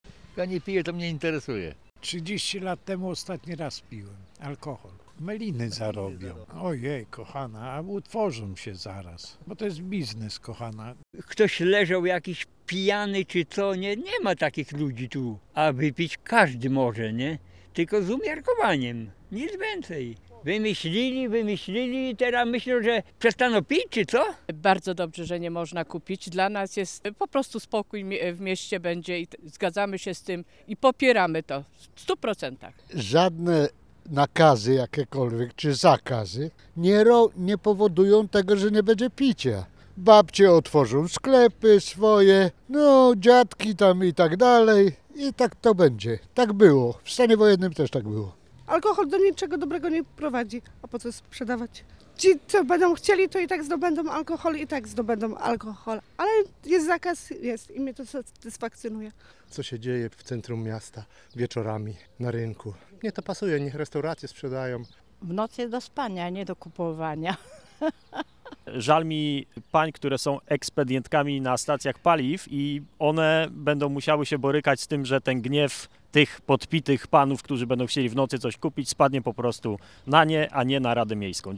prohibicja_Chojnice_sonda.mp3